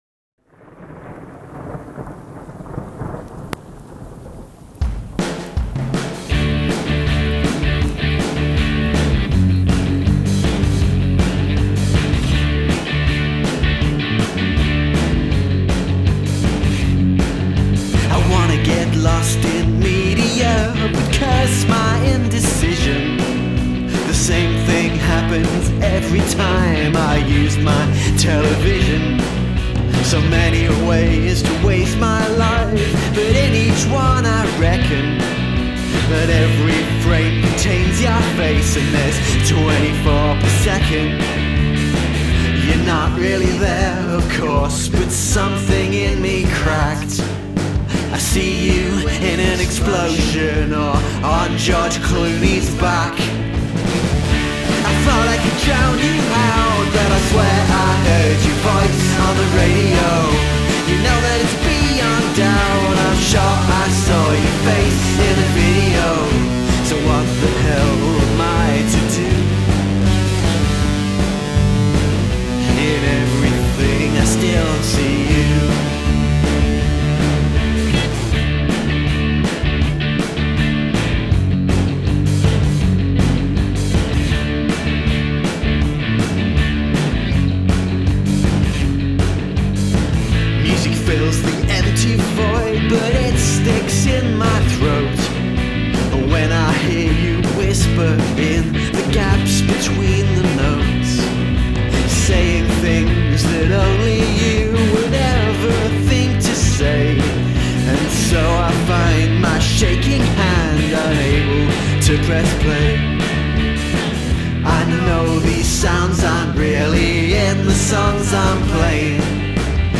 Use of field recording
I like the guitars right from the start.
Bookending field recordings seems to be a popular strategy.